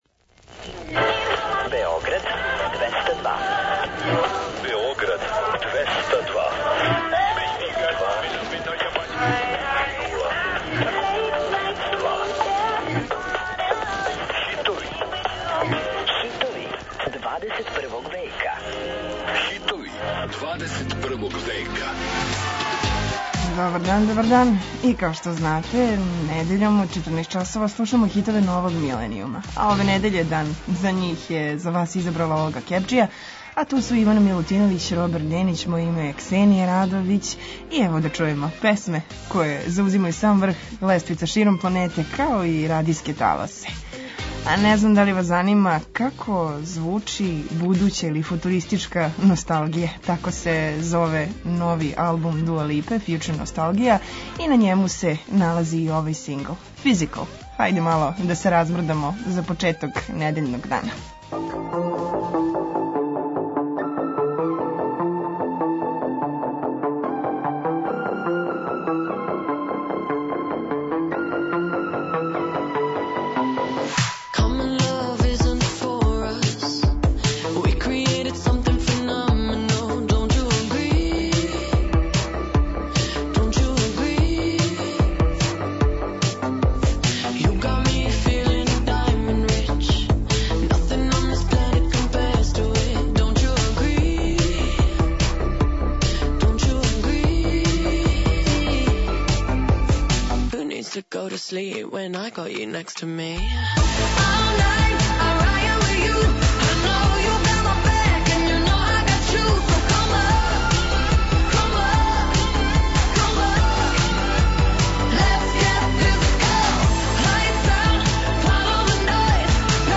Очекују вас највећи хитови 21. века!